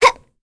Cleo-Vox_Jump.wav